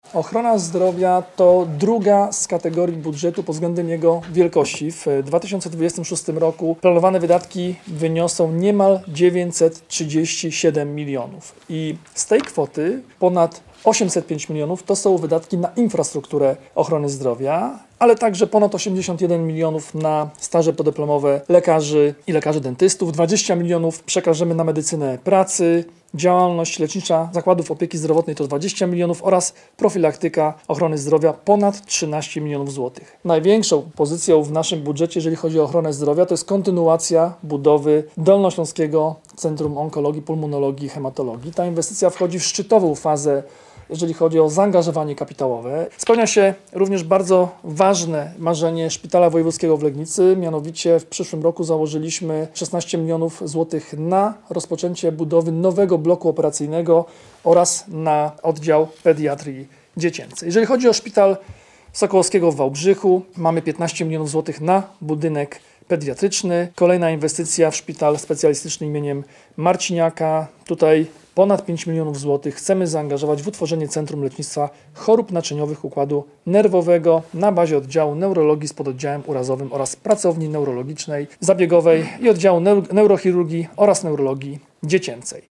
Podczas konferencji prezentującej założenia budżetowe członkowie zarządu województwa podkreślili, że przyszłoroczny budżet będzie większy od tegorocznego o 800 mln zł.
W obszarze zdrowia największą inwestycją jest kontynuacja budowy nowego szpitala onkologicznego we Wrocławiu, samorząd inwestuje też w ochronę zdrowia psychicznego dzieci i młodzieży, czy rozbudowę szpitali w województwie, dodaje Jarosław Rabczenko, członek zarządu Województwa Dolnośląskiego.